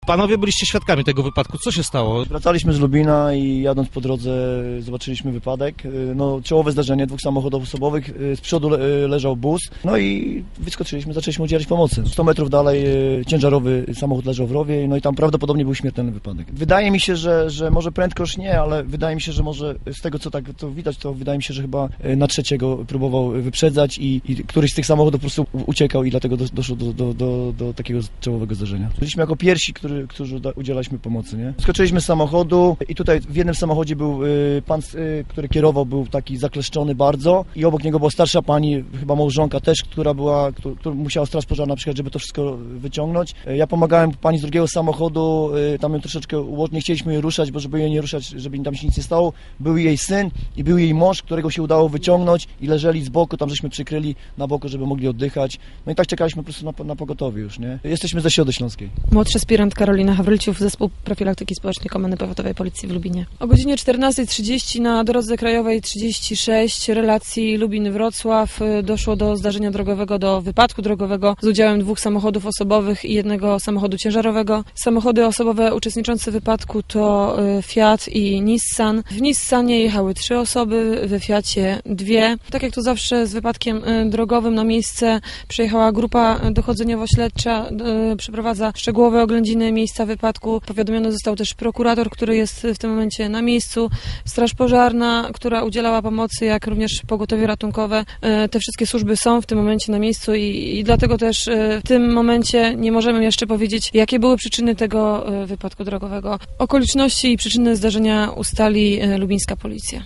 Na miejscu rozmawialiśmy ze świadkami